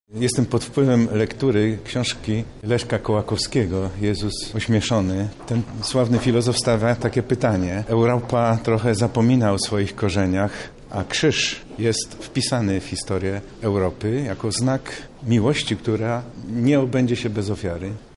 Krzyż jest wpisany w kulturę Europy, nasz krajobraz  – zauważa arcybiskup Stanisław Budzik, metropolita lubelski